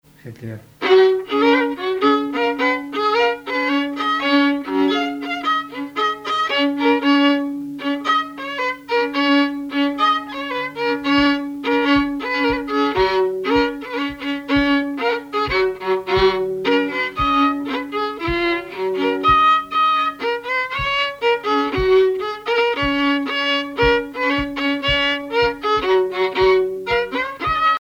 violoneux, violon
musique varieté, musichall
Pièce musicale inédite